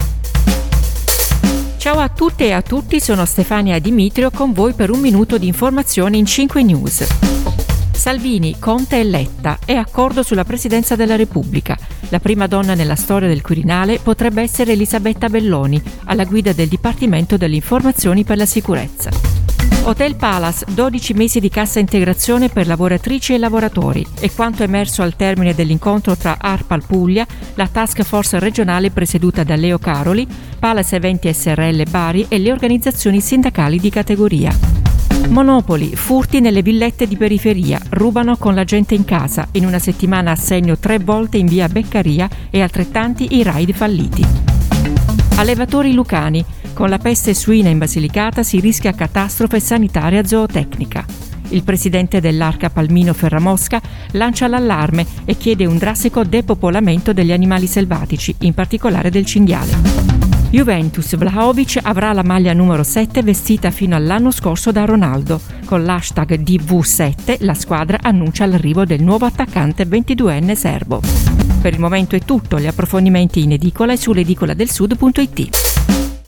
Giornale radio alle ore 7